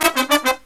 Index of /90_sSampleCDs/USB Soundscan vol.29 - Killer Brass Riffs [AKAI] 1CD/Partition B/09-108SL SB5